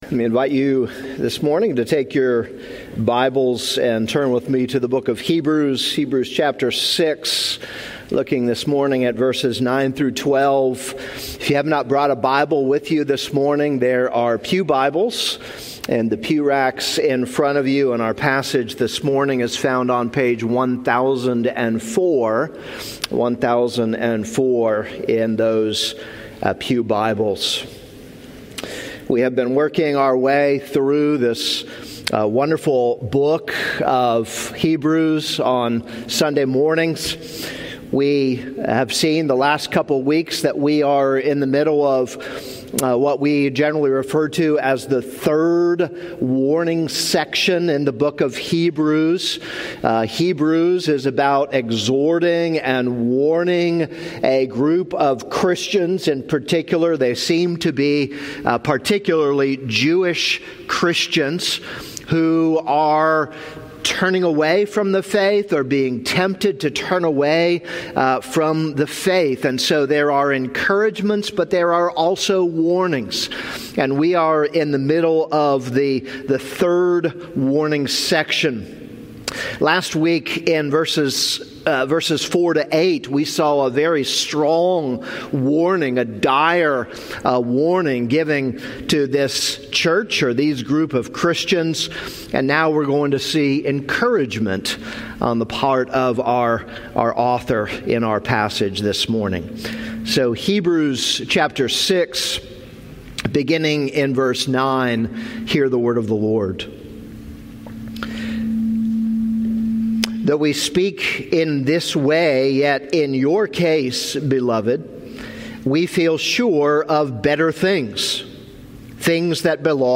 This is a sermon on Hebrews 6:9-12.